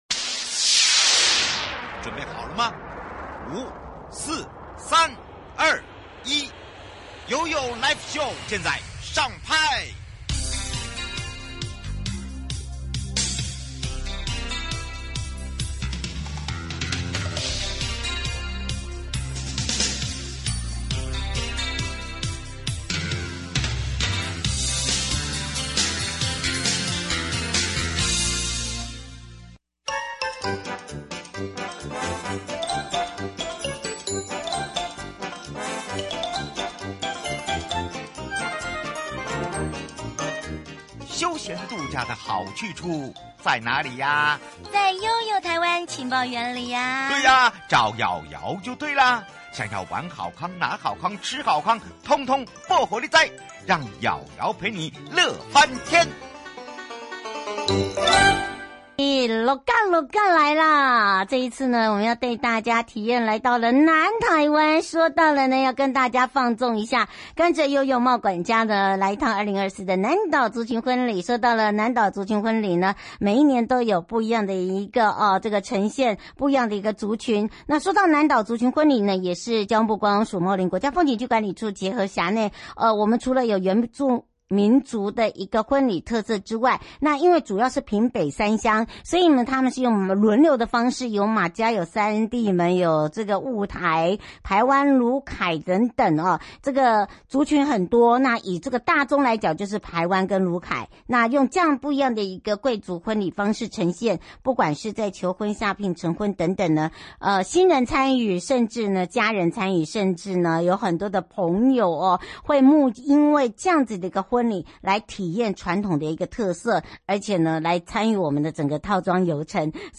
來自東海岸的Youtube節目 《推你個好東東》把最在地好吃好玩都推給你喔~ 受訪者